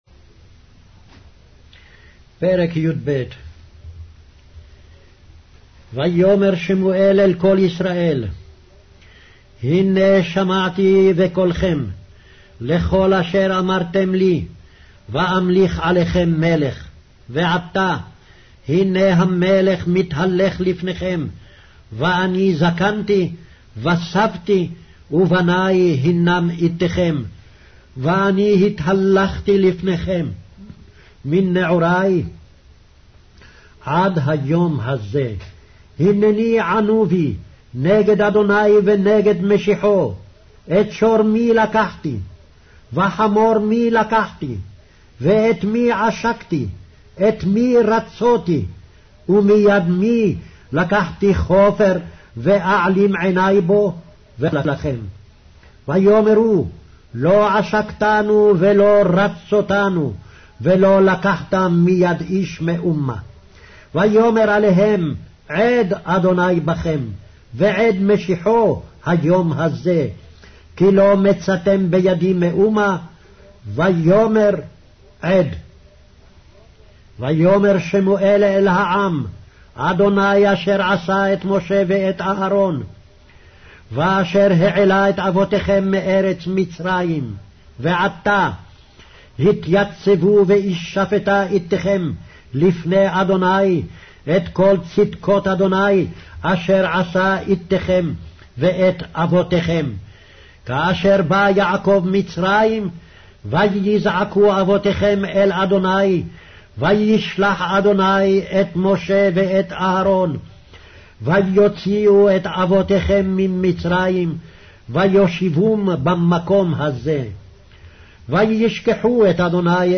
Hebrew Audio Bible - 1-Samuel 16 in Gnttrp bible version